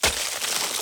Ice Freeze 1.ogg